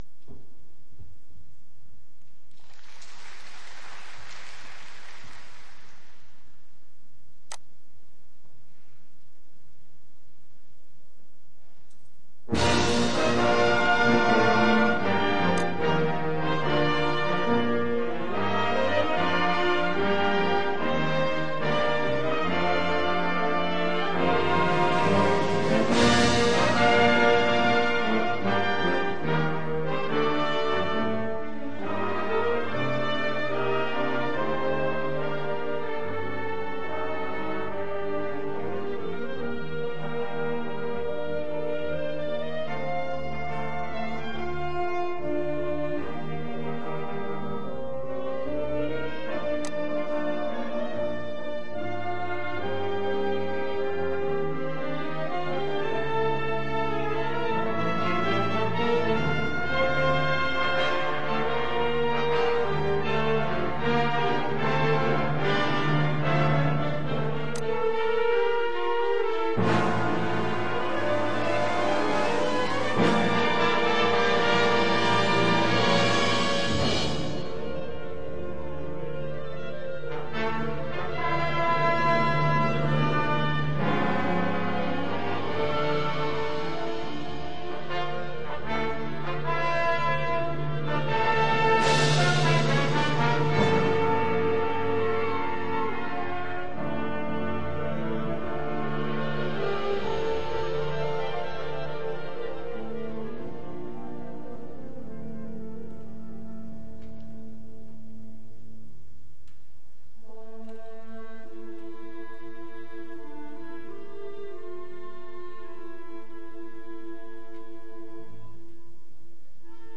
知多高吹奏楽部コンクールの記録
県大会小編成 銀賞